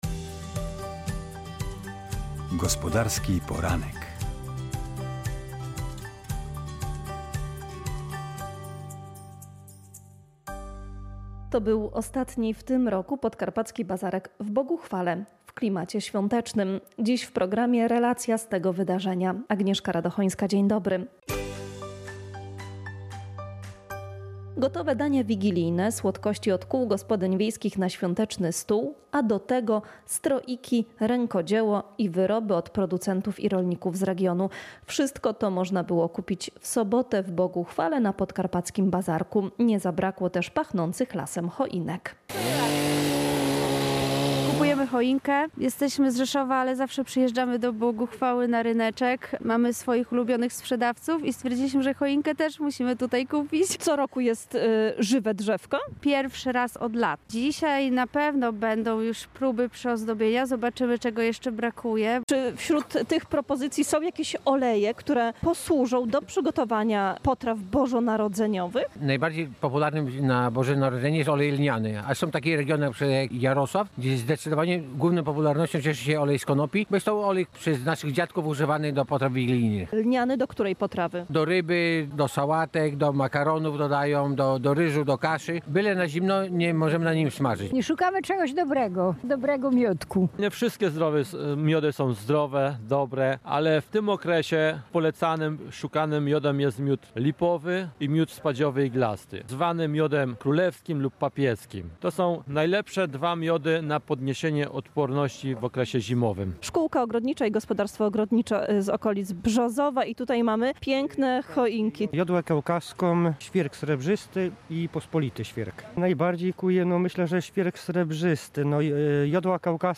To był ostatni w tym roku Podkarpacki Bazarek w Boguchwale. Tym razem w świątecznym wydaniu.